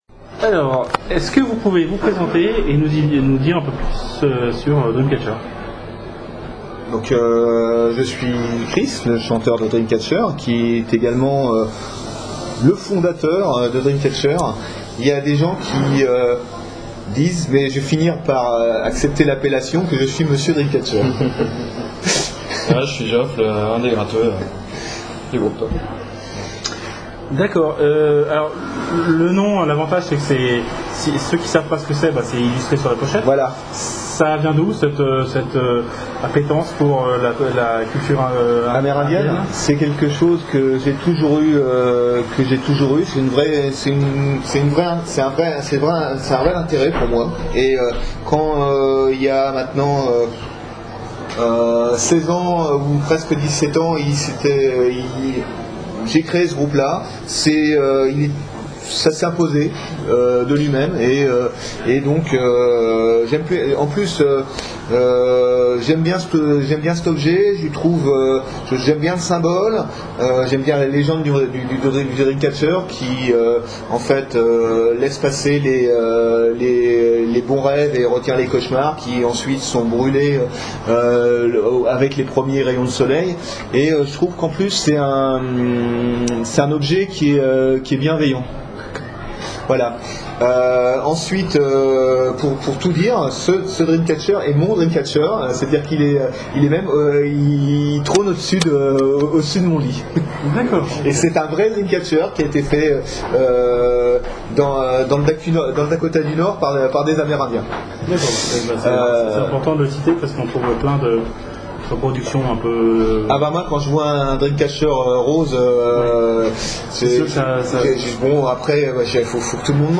DREAMCATCHER (interview